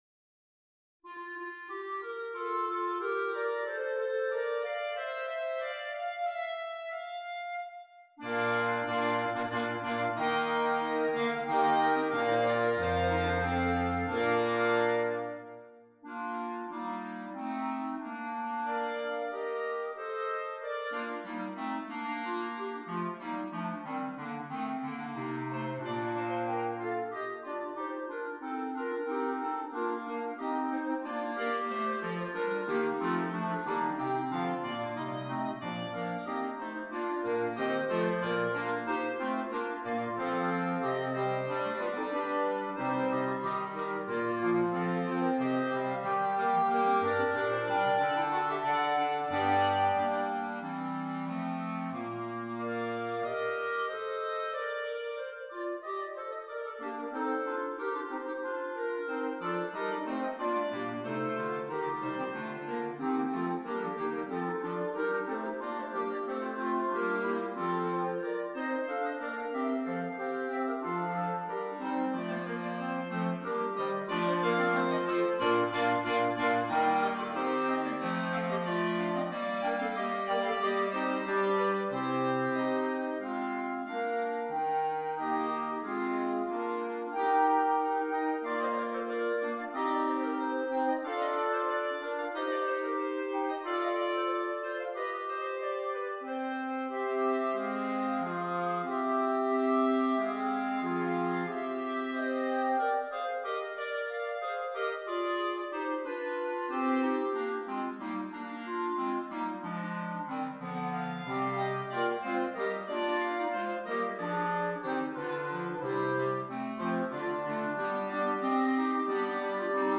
6 Clarinets, Bass Clarinet